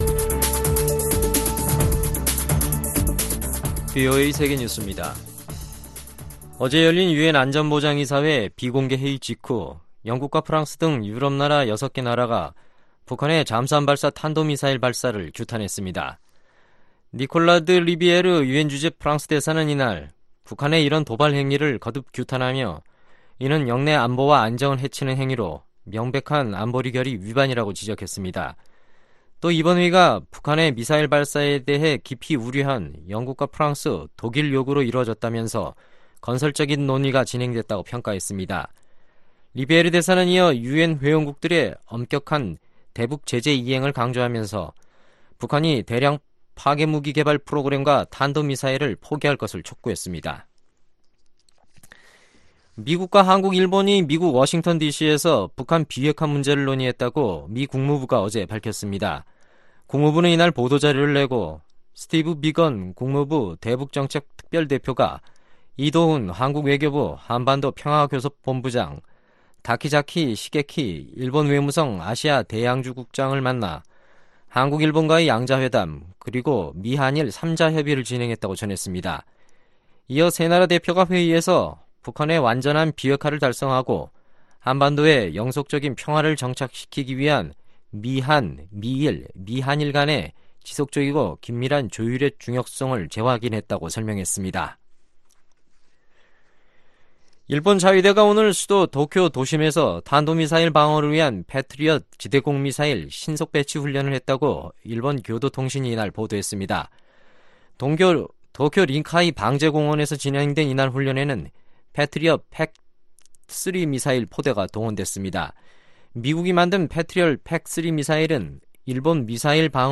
VOA 한국어 간판 뉴스 프로그램 '뉴스 투데이', 2019년 10월 9일 2부 방송입니다. 미-북 비핵화 실무협상 결렬을 계기로 미국 정계에서는 트럼프 행정부의 ‘외교 무능론’이 고조되고 있습니다. 유럽연합, EU는 최근 미-북 비핵화 실무협상이 결렬된 것과 관련해 양측이 조기에 이견을 해결하고 다시 만나길 바란다고 밝혔습니다.